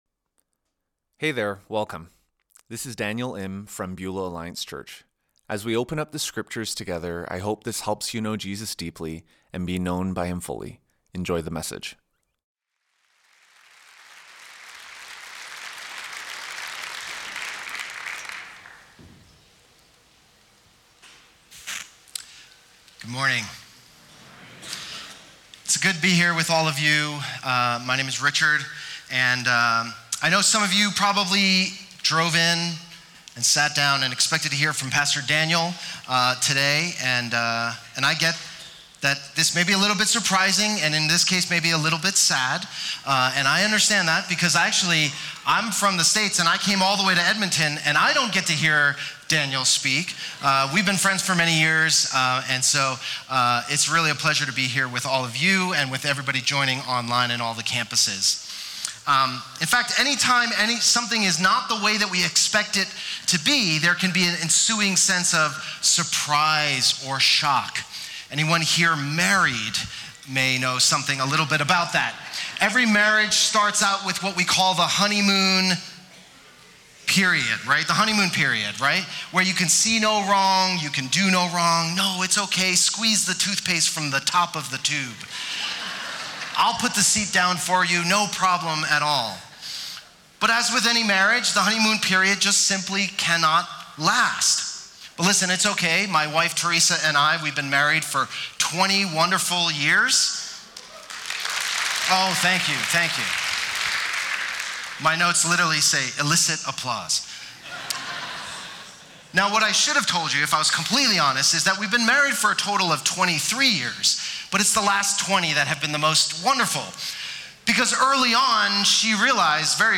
YouVersion Sermon Notes PDF Sermon Notes